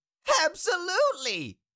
Cartoon Little Monster, Voice, Absolutely Sound Effect Download | Gfx Sounds
Cartoon-little-monster-voice-absolutely.mp3